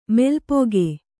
♪ melpoge